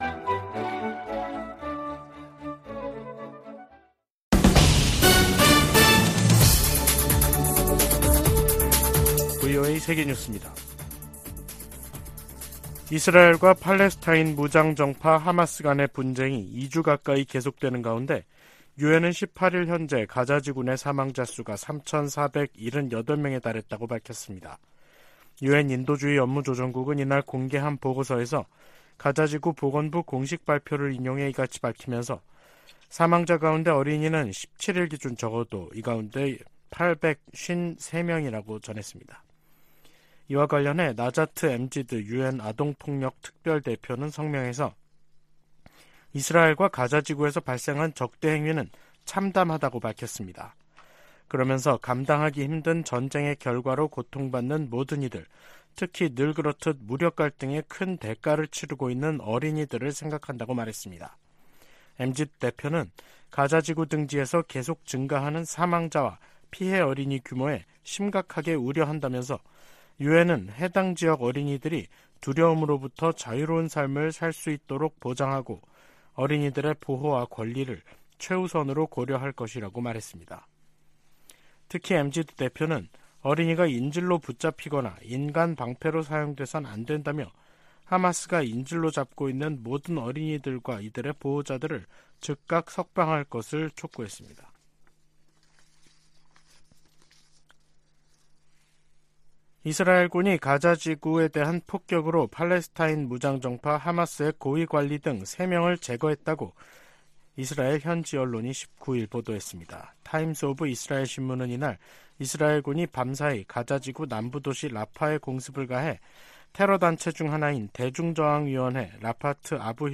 VOA 한국어 간판 뉴스 프로그램 '뉴스 투데이', 2023년 10월 19일 3부 방송입니다. 북한을 방문한 세르게이 라브로프 러시아 외무장관은 양국 관계가 질적으로 새롭고 전략적인 수준에 이르렀다고 말했습니다. 미 상원의원들은 북-러 군사 협력이 러시아의 우크라이나 침략 전쟁을 장기화하고, 북한의 탄도미사일 프로그램을 강화할 수 있다고 우려했습니다. 북한이 암호화폐 해킹을 통해 미사일 프로그램 진전 자금을 조달하고 있다고 백악관 고위 관리가 밝혔습니다.